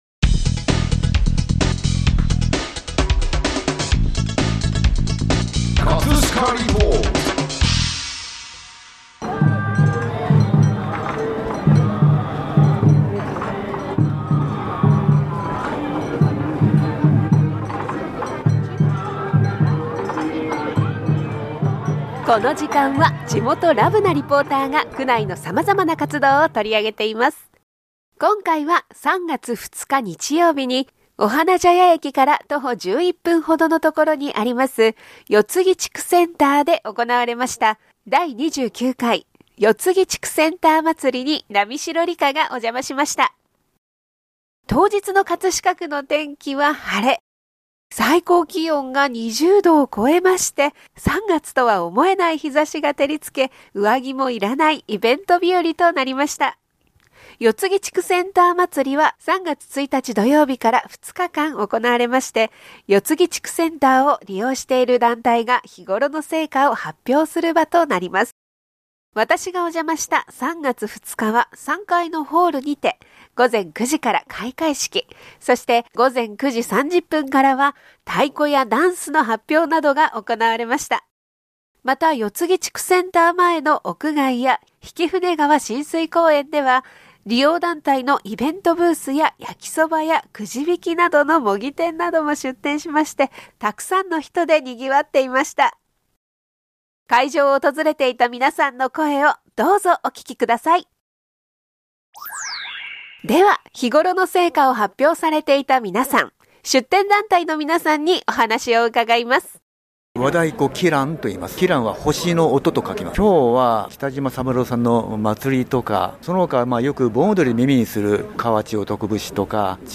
【葛飾リポート】
会場を訪れていた皆さんの声をどうぞお聞きください！